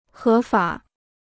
合法 (hé fǎ) Free Chinese Dictionary